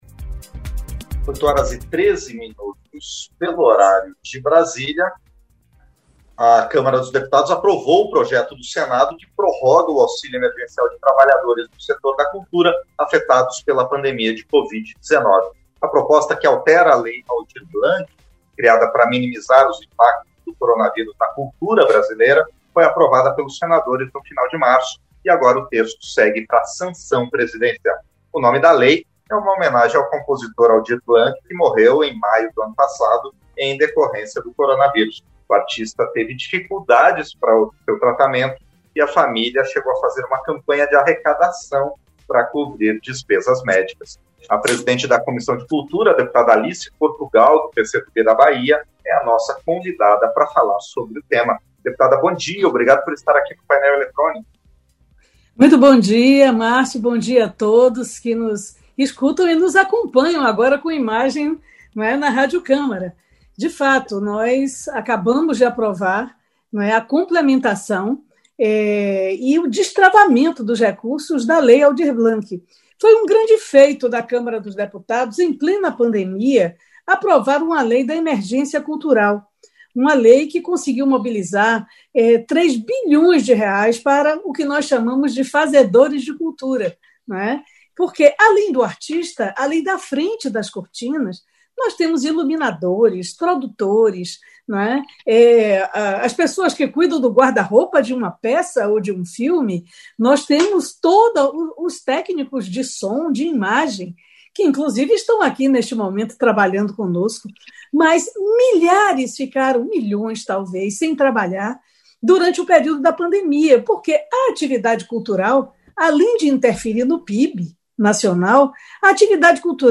Entrevista - Dep. Alice Portugal (PCdoB-BA)